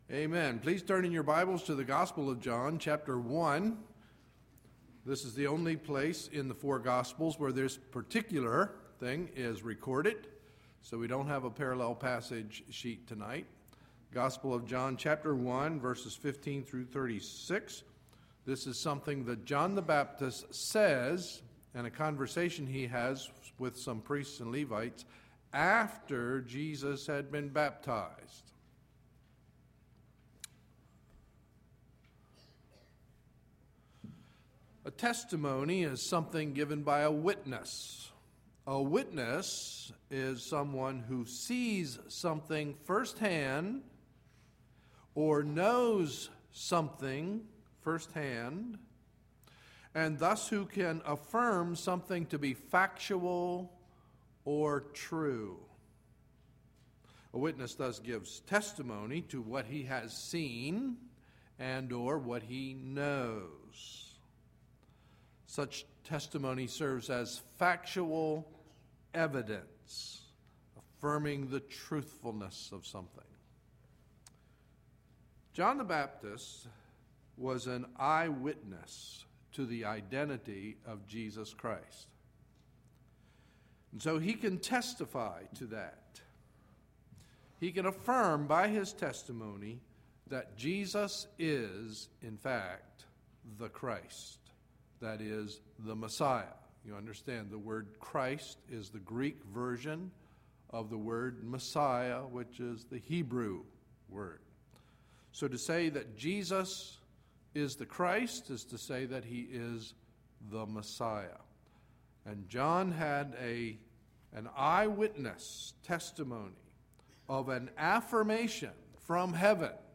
Sunday, January 22, 2012 – Evening Message